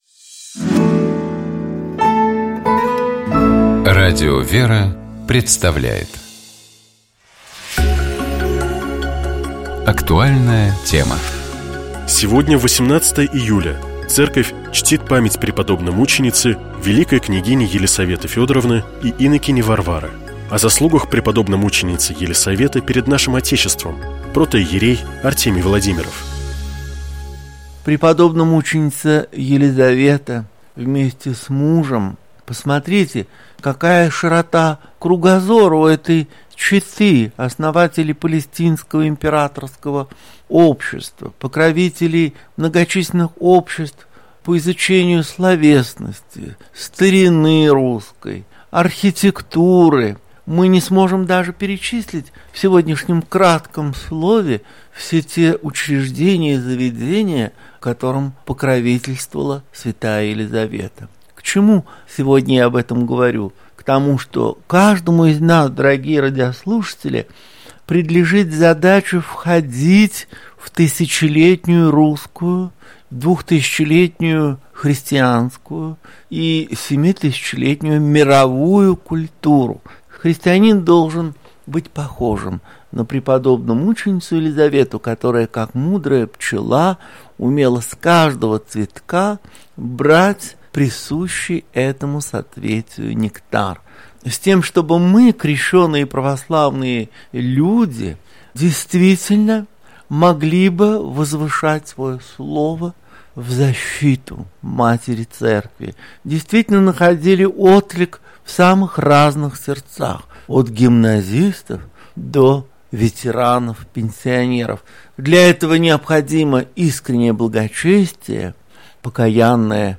Это «Светлый вечер» на Светлом радио